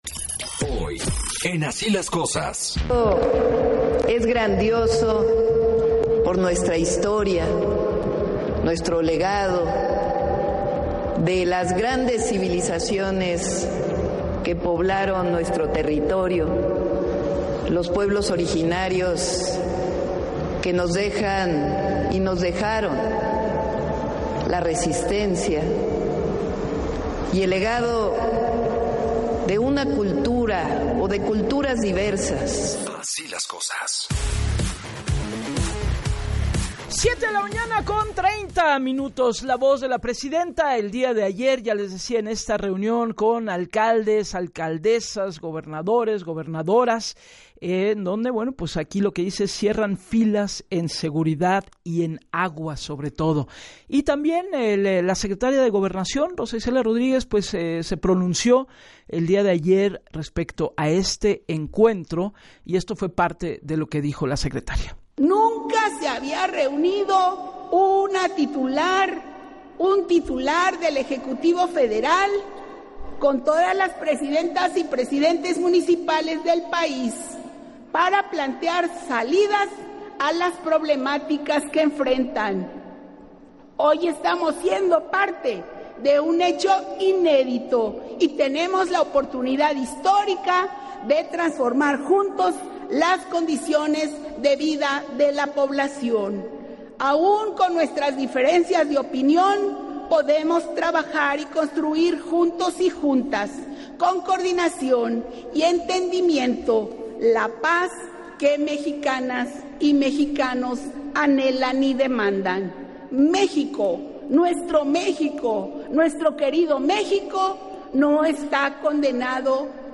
En entrevista para “Así las Cosas” con Gabriela Warkentin, la alcaldesa compartió que en la reunión con el gobierno federal y capitalino y con la asistencia de más de 2 mil alcaldes se llevó a cabo la firma de un convenio de colaboración signado por los gobernadores de todos los partidos, quienes más allá de sus intereses políticos, hablaron de las necesidades y retos que se deben coordinar desde los tres niveles de gobierno.